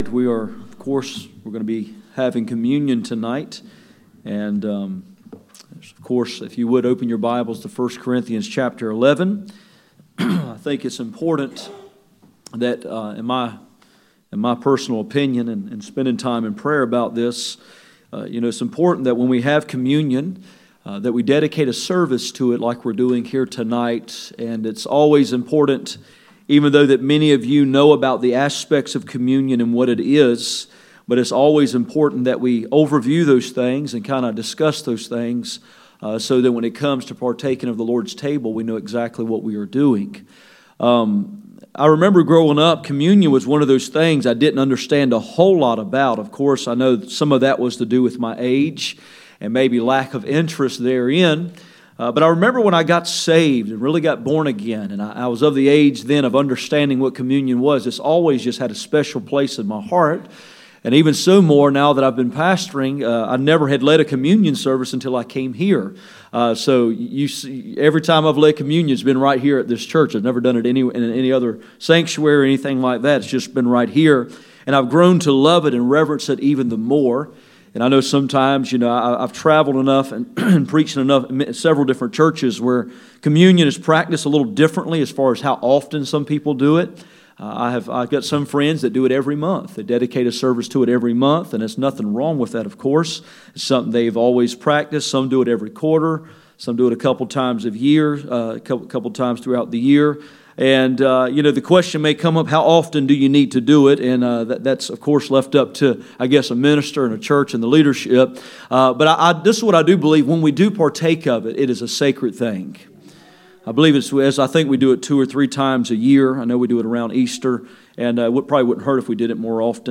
None Passage: 1 Corinthians 11:23-34 Service Type: Sunday Evening %todo_render% « Cross Words